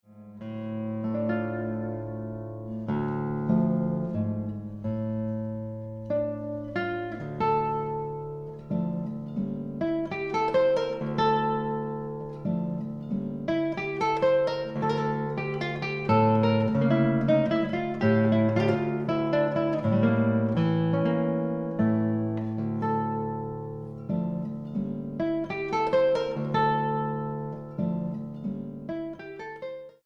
barcarola